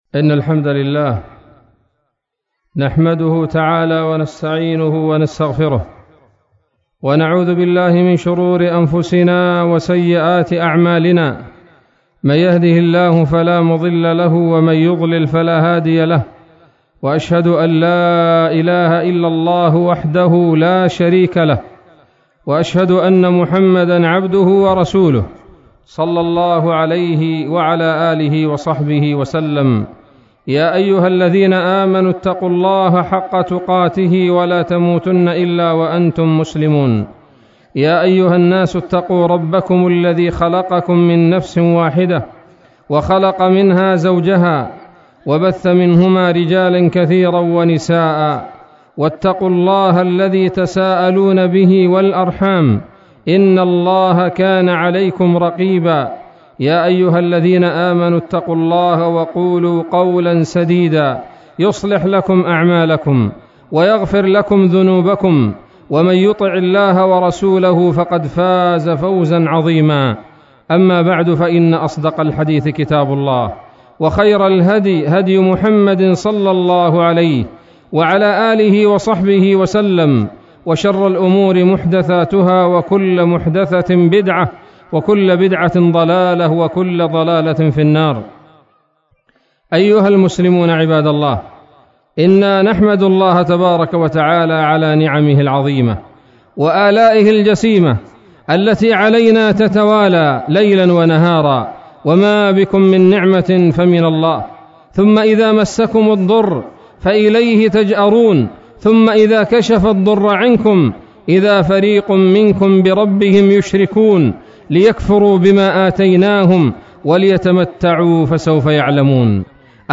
خطبة عيد الفطر المبارك 1440هـ
khotbat_eed_alfetr_1440.mp3